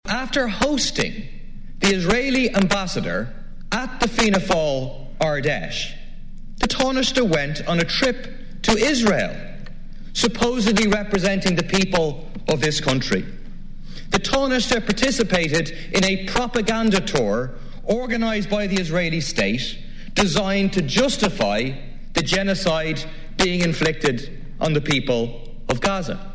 Speaking in the Dail, People Before Profit TD Paul Murphy accused Taniaste Michael Martin of taking part in a propaganda exercise in Israel last week: